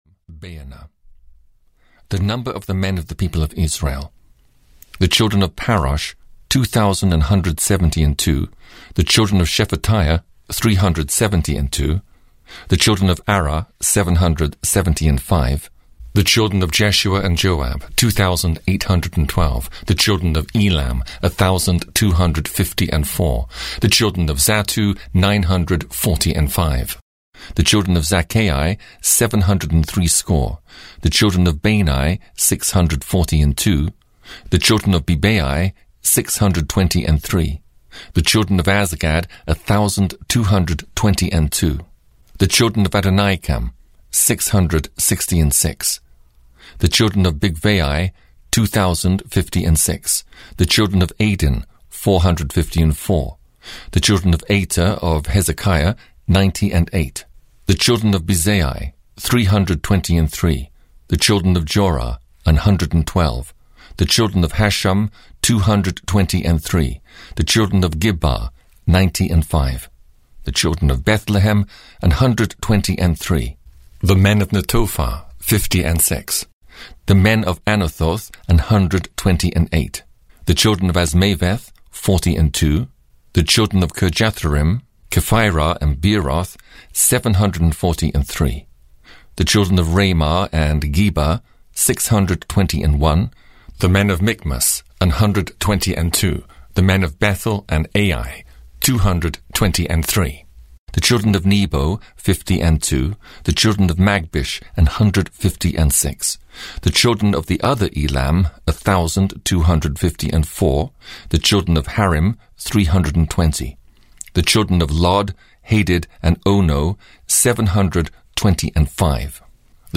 Audio knihaThe Old Testament 15 - Ezra (EN)
Ukázka z knihy